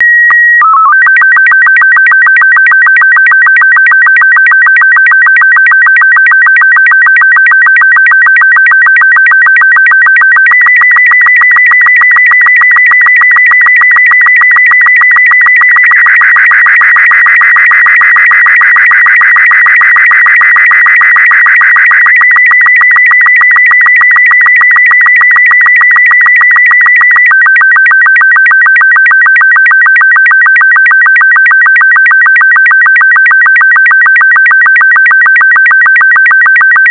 Slow Scan Television (30 points)